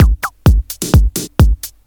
Electrohouse Loop 128 BPM (14).wav